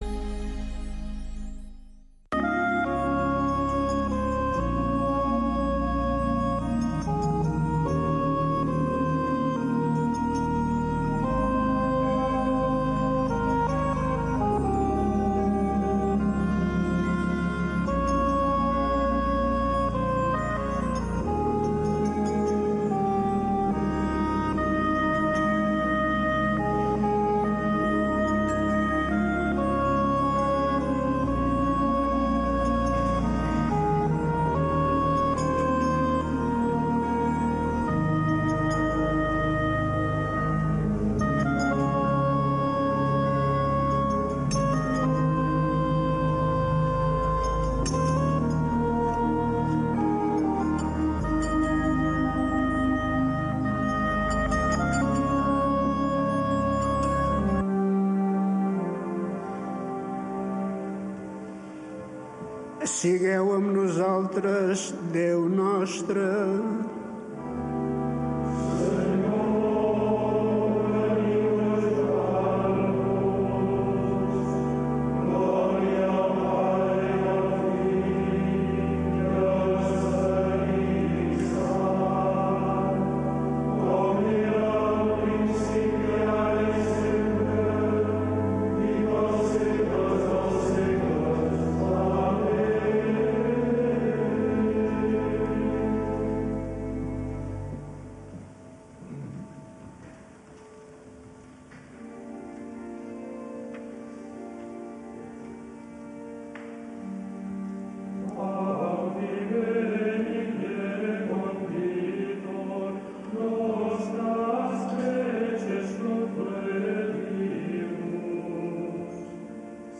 Pregària del vespre per donar gràcies al Senyor. Amb els monjos de Montserrat, cada dia a les 18.45 h